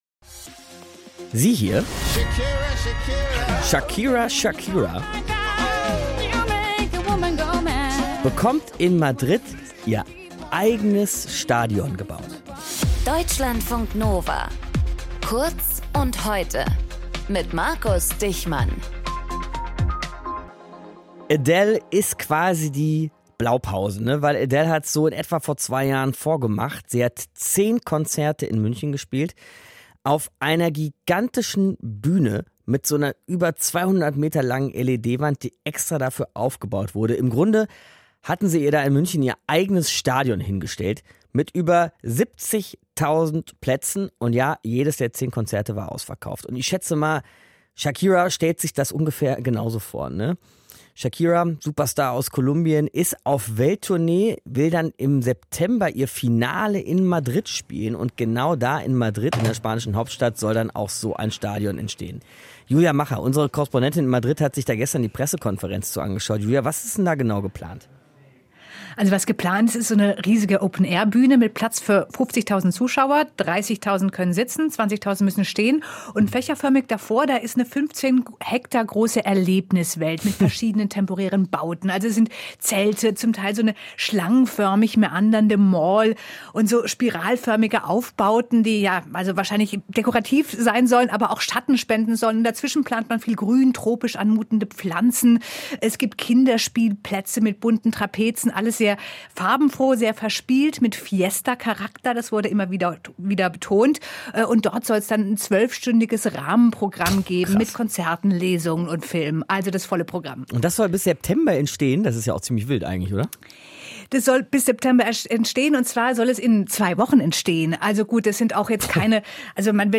Moderation:
Gesprächspartnerin: